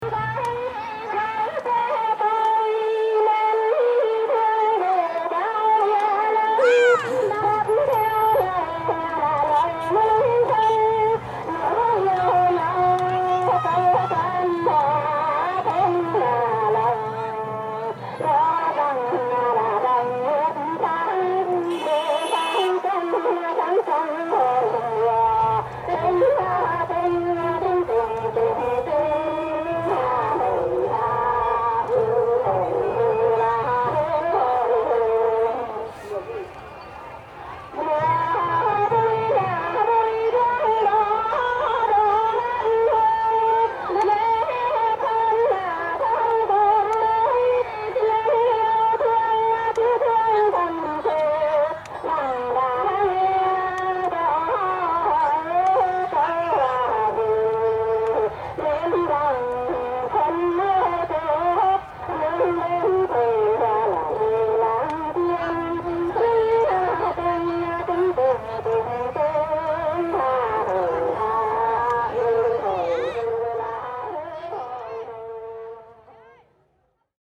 I recorded a lot of sounds in Vietnam, and here are some of those harder to categorize:
- Distorted singing at festival (from afar), near Bac Ninh (1:17)
ambientfestivaldistortion.mp3